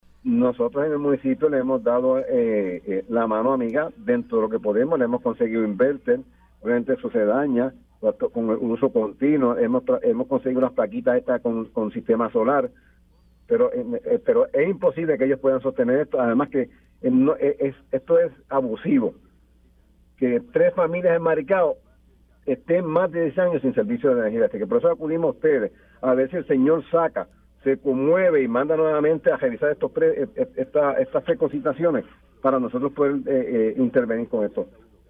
El alcalde de Maricao, Wilfredo ‘Juny’ Ruiz denunció en Pega’os en la Mañana que LUMA Energy duplicó el costo de unas propuestas que les sometieron para proveer servicio eléctrico a tres familias maricaeñas que no cuentan con luz desde hace 10 años.